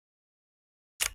camera_shutter.ogg